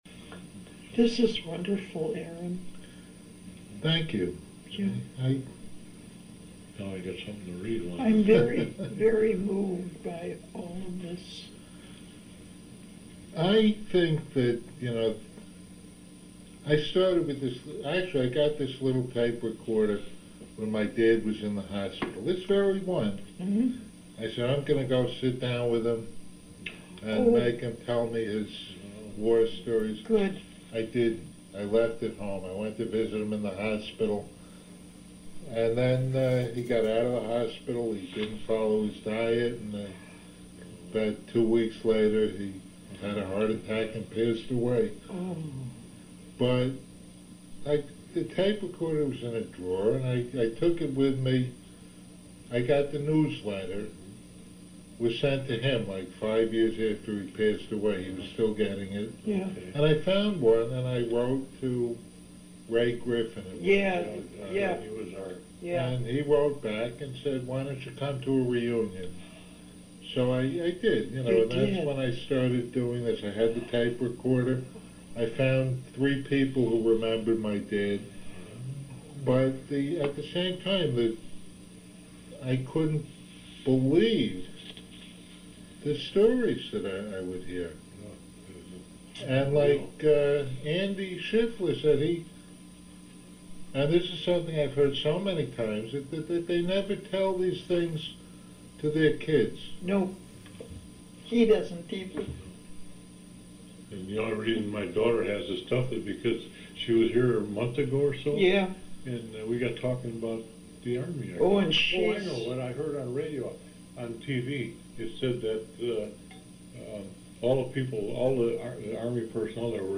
Why I began recording veterans' stories